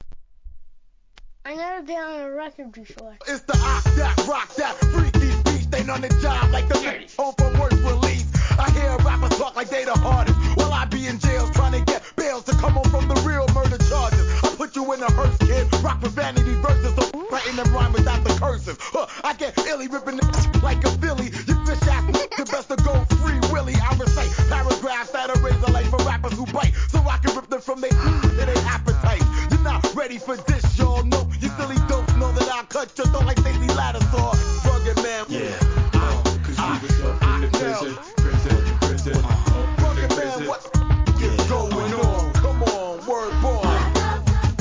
HIP HOP/R&B
DOPEアングラ!